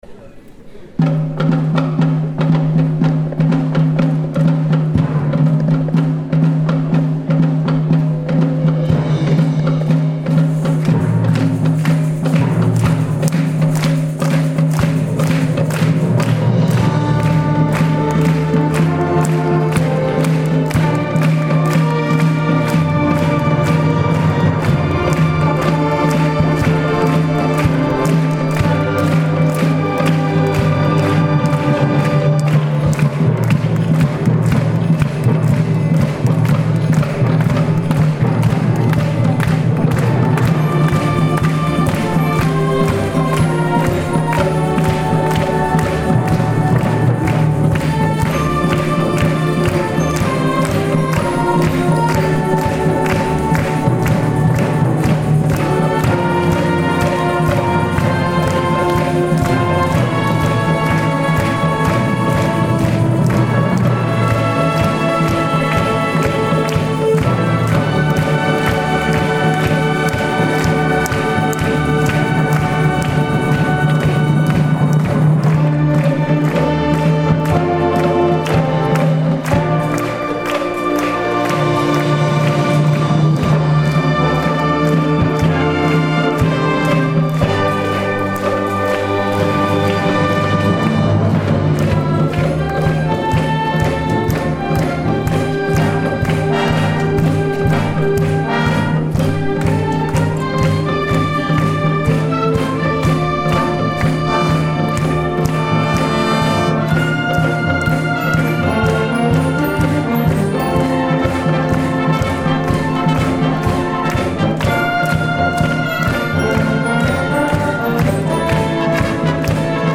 午後の『あさフェス』の吹奏楽部と有志バンドの演奏をアップします。